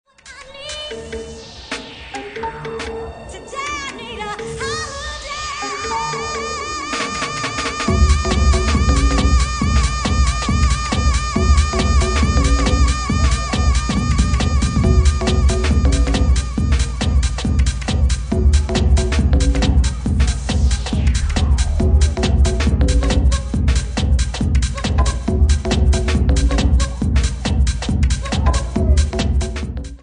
at 138 bpm